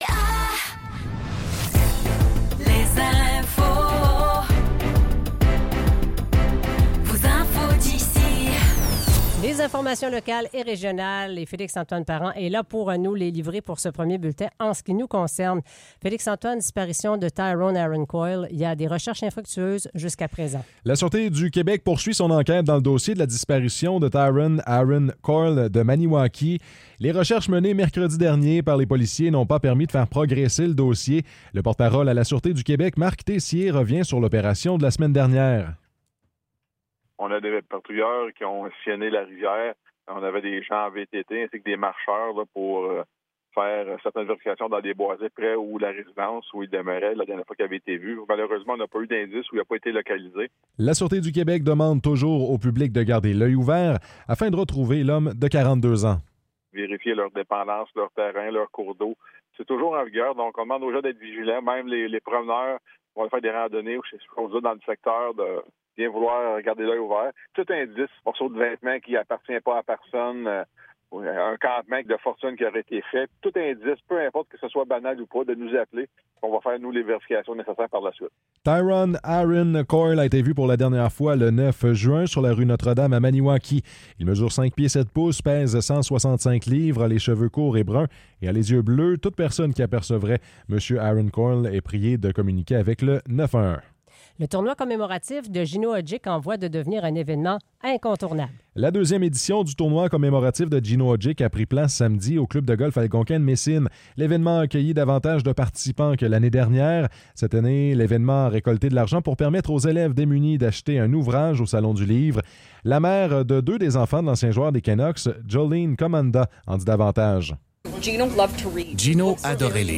Nouvelles locales - 15 juillet 2024 - 15 h